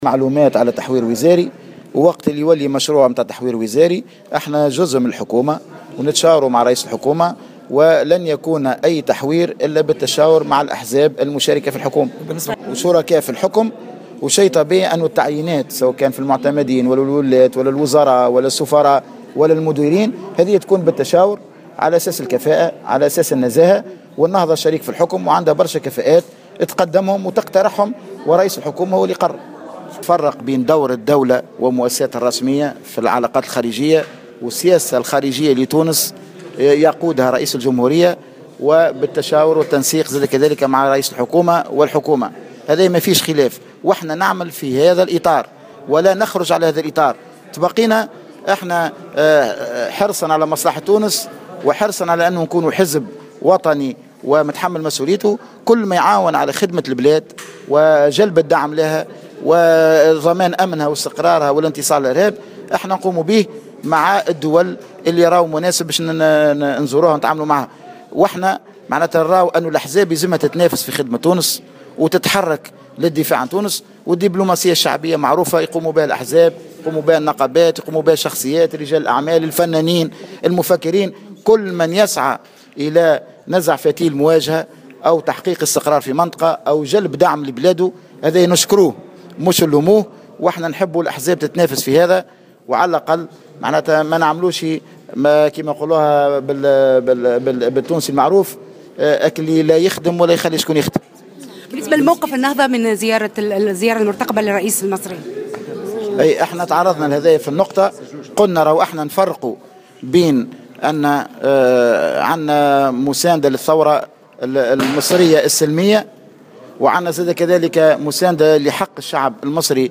وأكد في تصريحات صحفية على هامش ندوة عقدها "شورى النهضة"